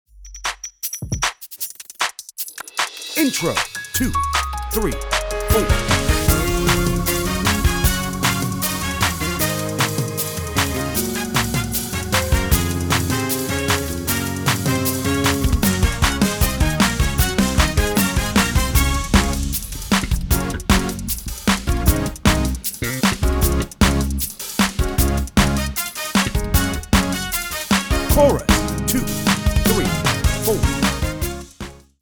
Tempo: 154bpm Key: Db https